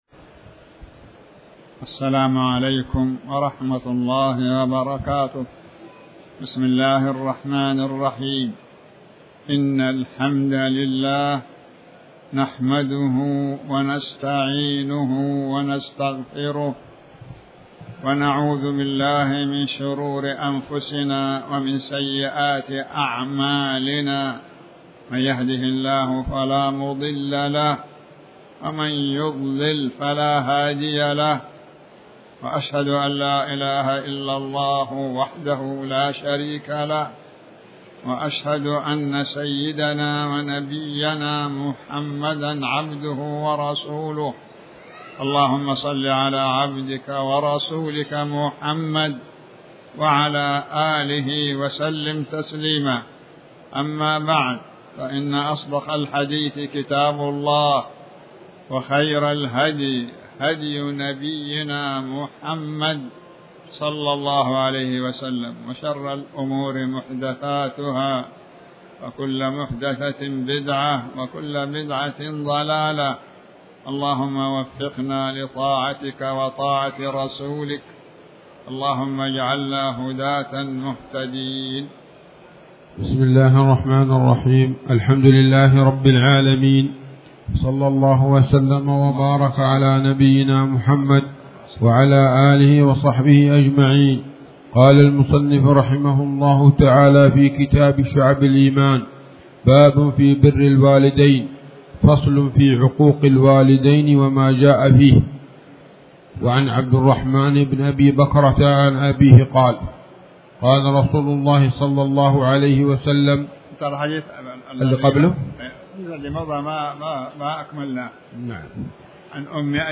تاريخ النشر ٨ محرم ١٤٤٠ هـ المكان: المسجد الحرام الشيخ